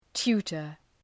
Προφορά
{‘tu:tər}